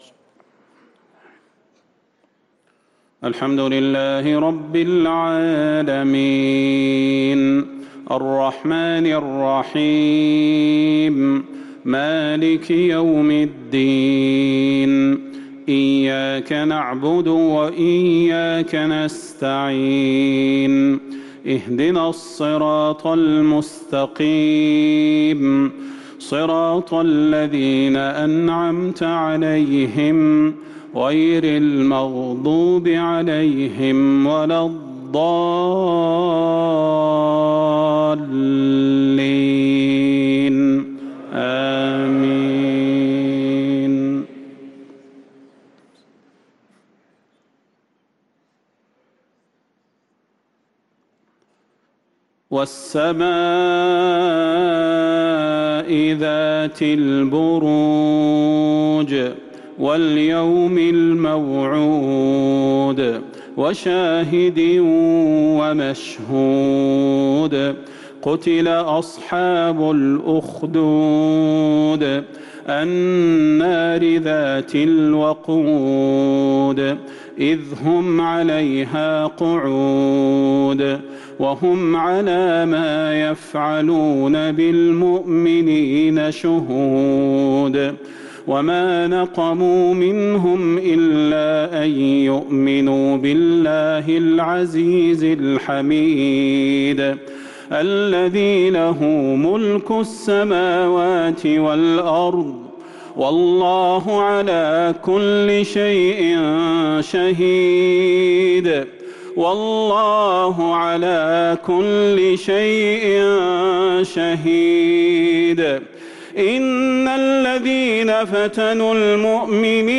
صلاة العشاء للقارئ صلاح البدير 9 ربيع الآخر 1445 هـ
تِلَاوَات الْحَرَمَيْن .